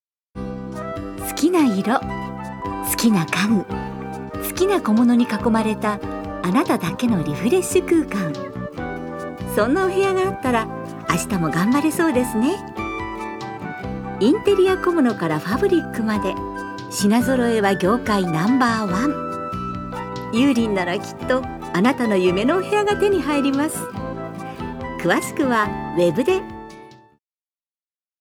ナレーション２